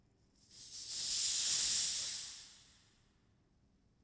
steam.wav